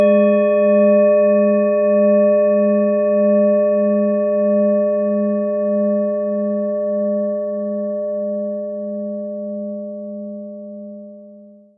Planetenschale®
Im Sound-Player - Jetzt reinhören können Sie den Original-Ton genau dieser Schale anhören.
Durch die überlieferte Fertigung hat sie dafür diesen besonderen Spirit und eine Klangschwingung, die unser Innerstes berührt.
PlanetentonUranus
SchalenformBihar
MaterialBronze